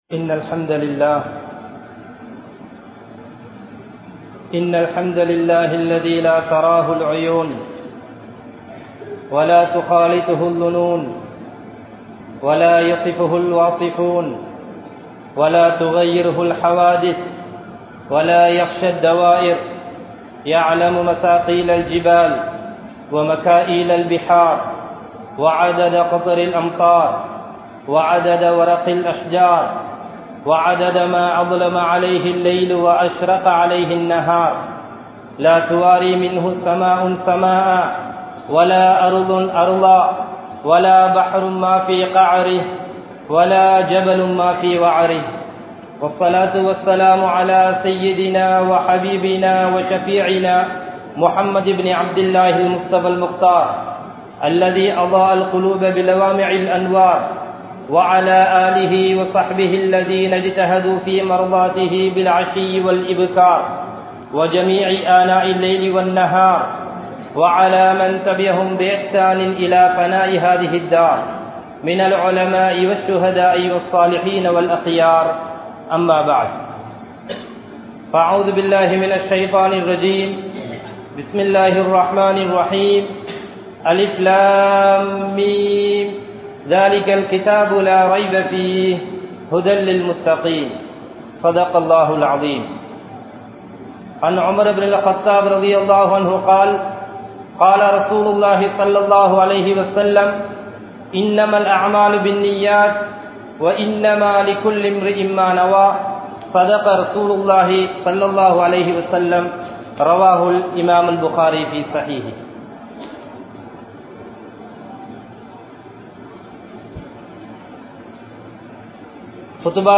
Islamiya Pengalukku Ethirana Sathihal (இஸ்லாமிய பெண்களுக்கு எதிரான சதிகள்) | Audio Bayans | All Ceylon Muslim Youth Community | Addalaichenai